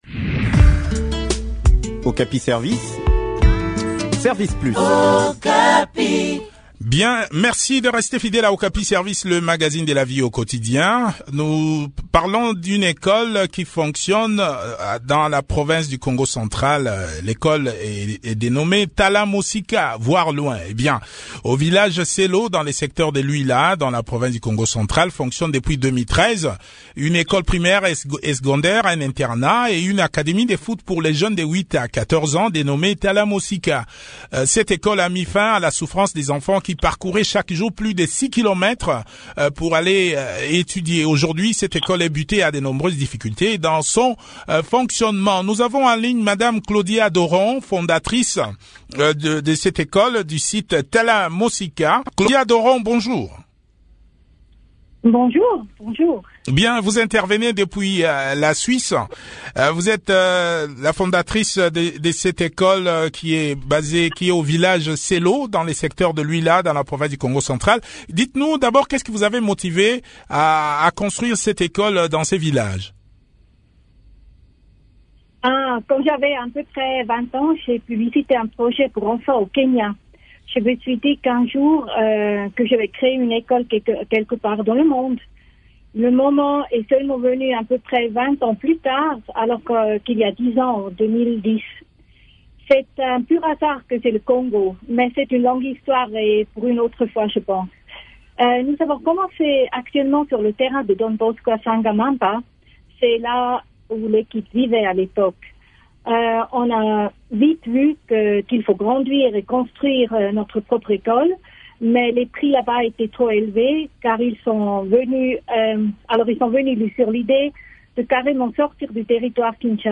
Le point sur les difficultés rencontrées par les gestionnaires de cette école dans cet entretien